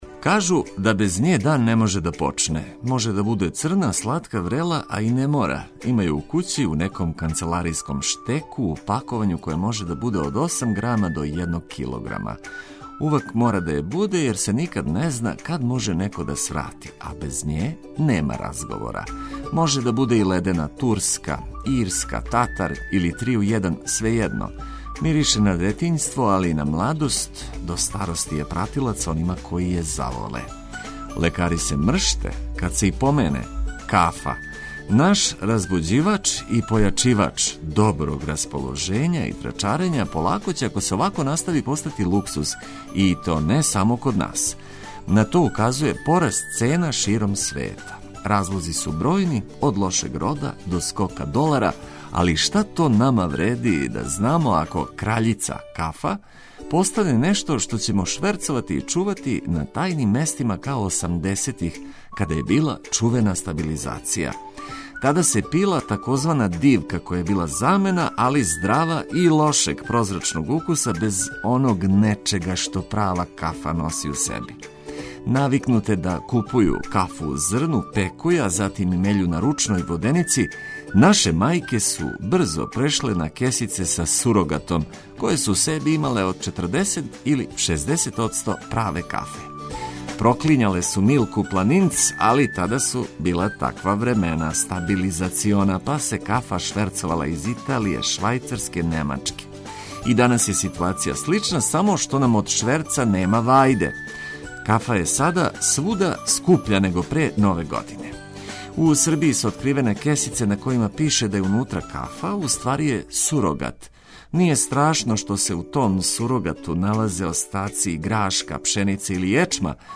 Tokom jutra čućete informacije od značaja za sve koji nas osluškuju protkane vedrom muzikom za rasanjivanje.